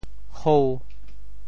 “侯”字用潮州话怎么说？
hou5.mp3